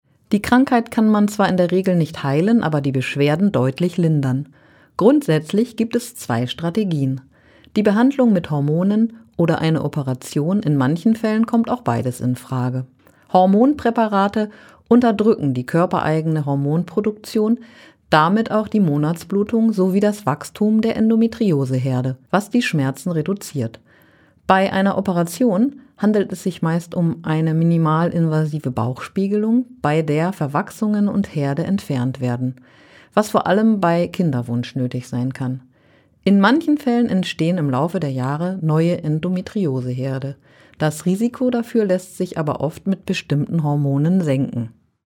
33_oton_endometriose_1_behandlung.mp3